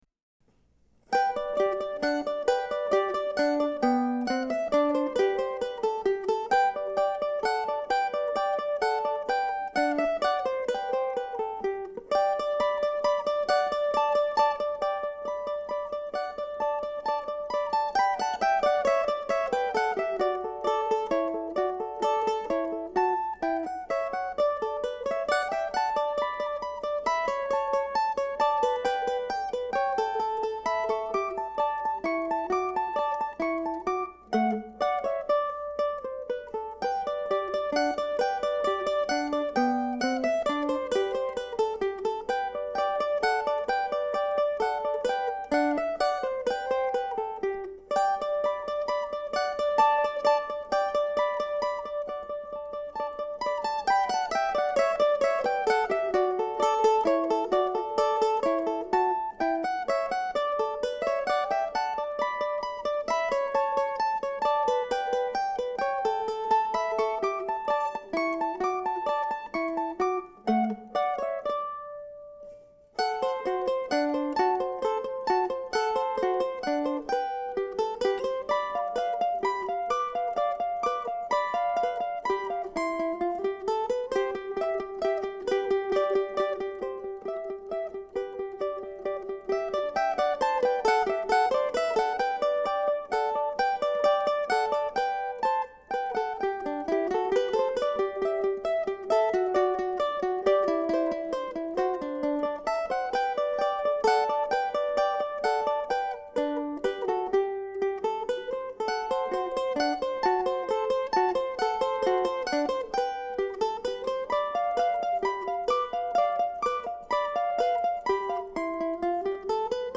In this post I am presenting an arrangement of part IV of the sonata, the concluding Vivace, for two mandolins (or mandolin and violin, etc.). In arranging this music for two mandolins I have pulled notes and phrases from the bass line to provide material for each player during what were measures of rest in the original.